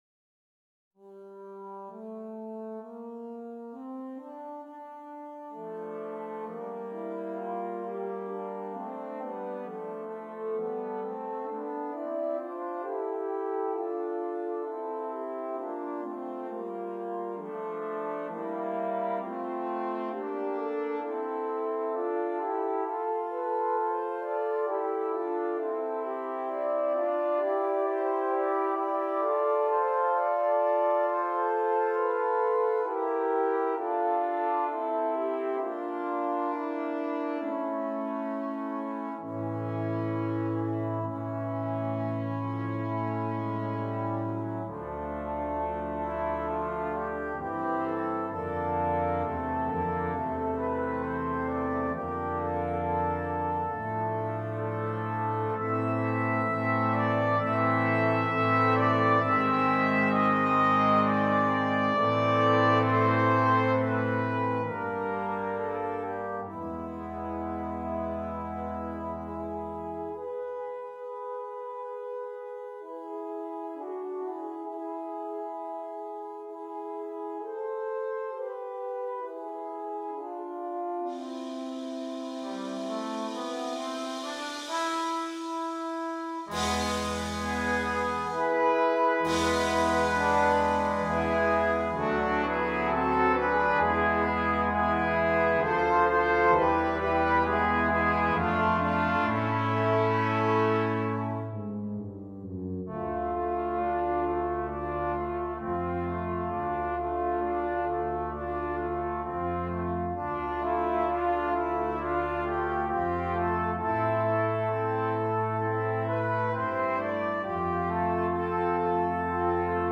Brass Quintet (optional Percussion)
There is an optional percussion part provided.